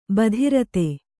♪ badhirate